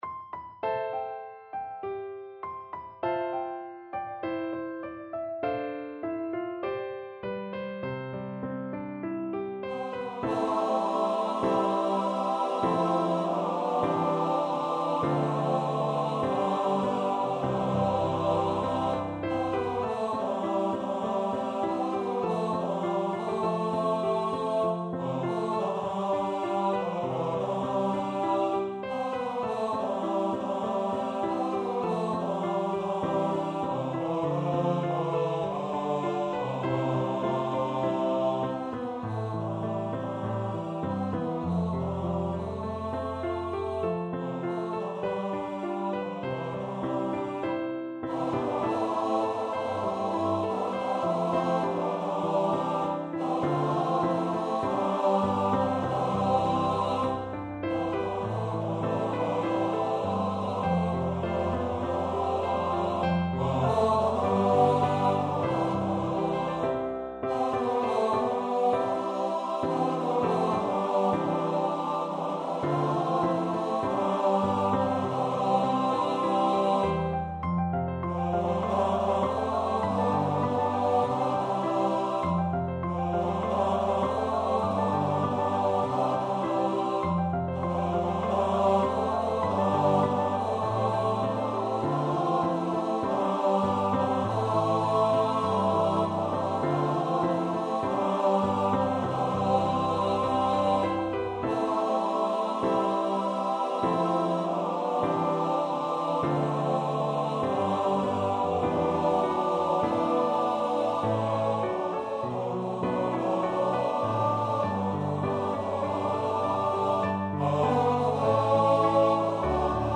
Fields of Gold TTBB.mp3